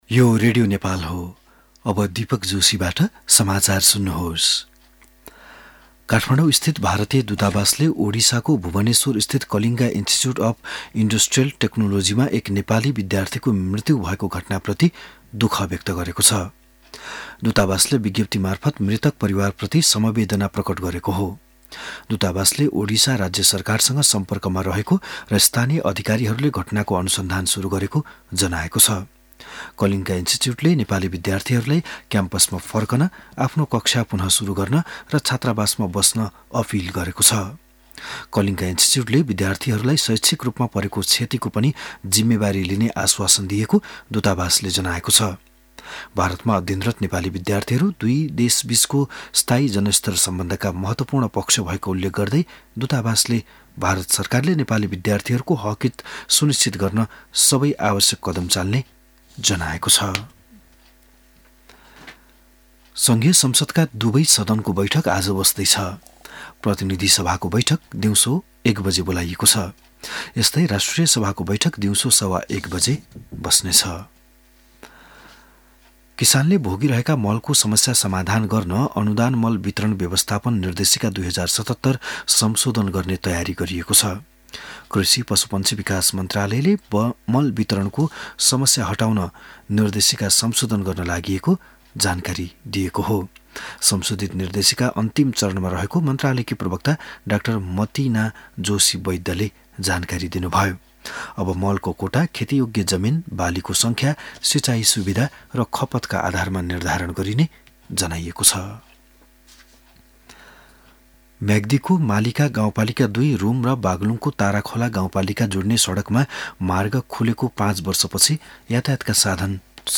बिहान ११ बजेको नेपाली समाचार : ७ फागुन , २०८१
11-am-news-6.mp3